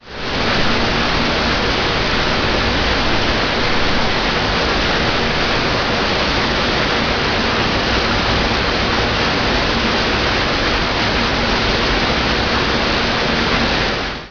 filtration-unit.ogg